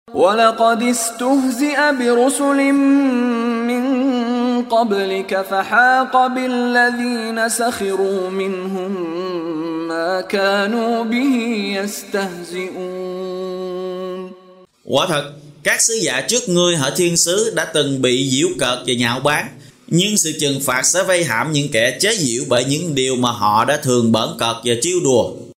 Đọc ý nghĩa nội dung chương Al-Ambiya bằng tiếng Việt có đính kèm giọng xướng đọc Qur’an